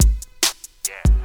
JJKicks (12).wav